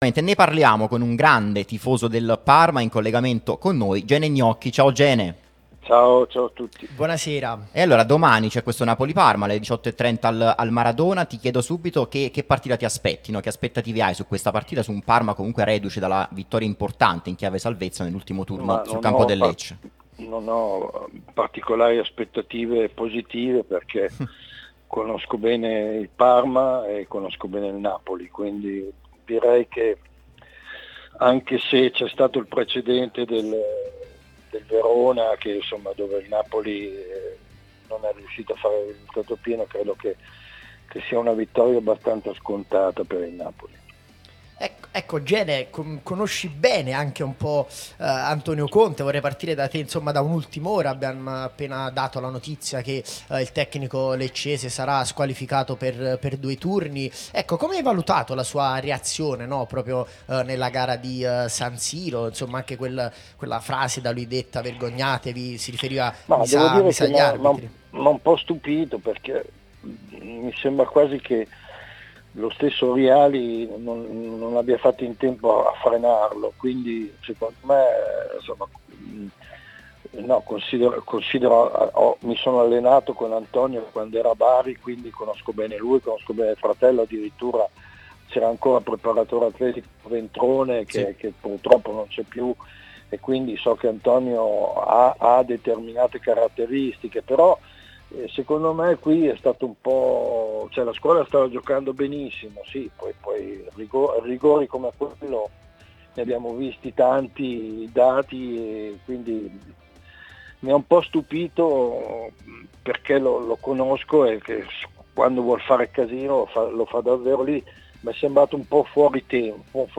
L'attore comico e grande tifoso del Parma Gene Gnocchi è intervenuto nel corso di 'Napoli Talk' sulla nostra Radio Tutto Napoli, prima radio tematica sul Napoli, che puoi ascoltare/vedere qui sul sito, in auto col DAB Campania o sulle app gratuite (scarica qui per Iphone o qui per Android).